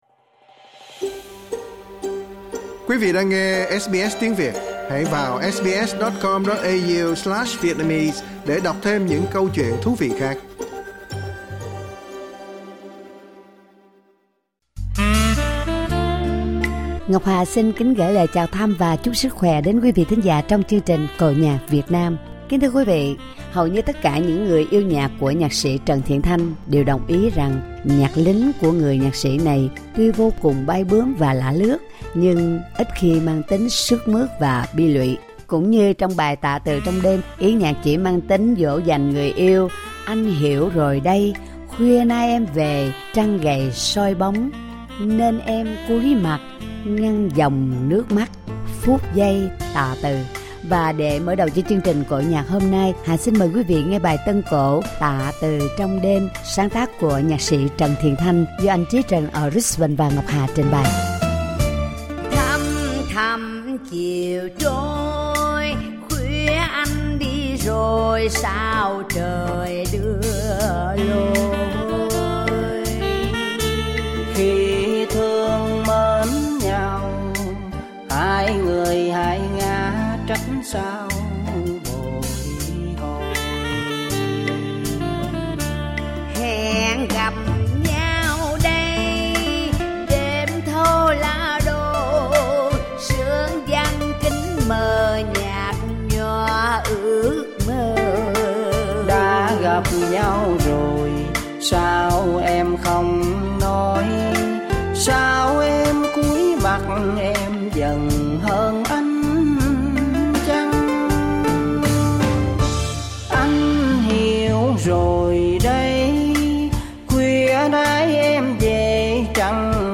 tân cổ